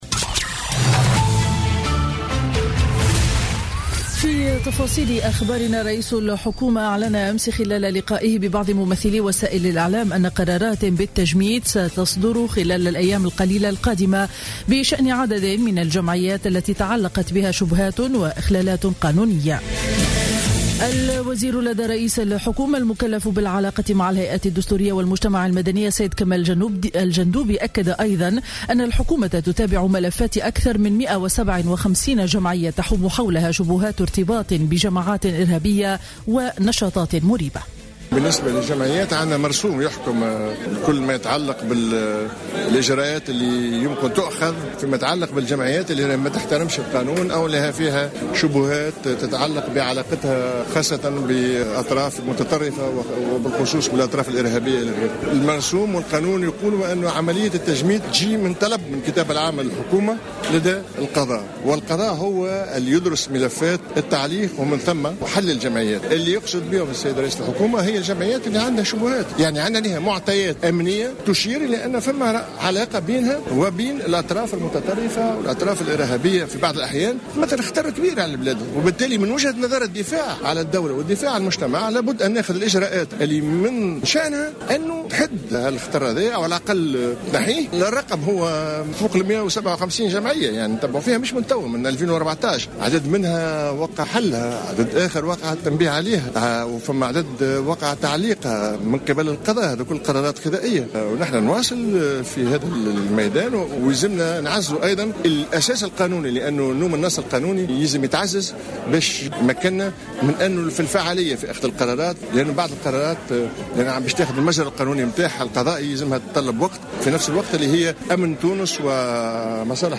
نشرة أخبار السابعة صباحا ليوم الأحد 24 أفريل 2016